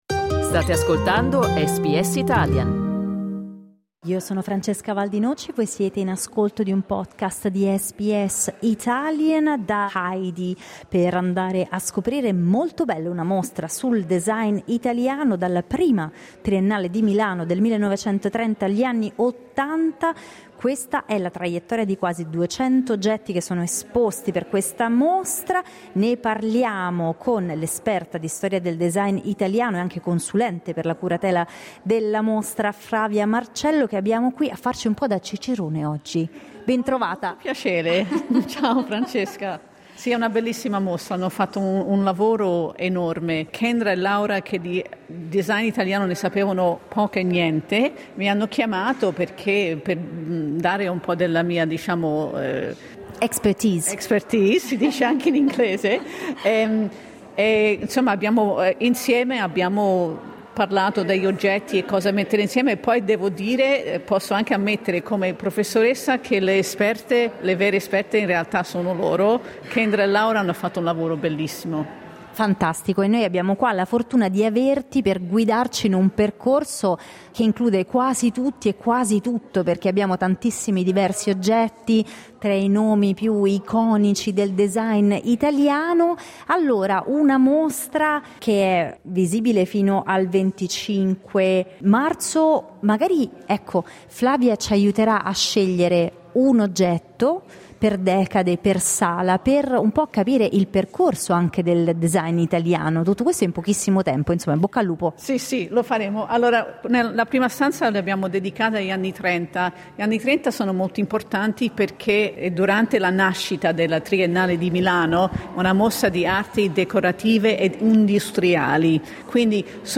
Ascolta l'audio guida alla mostra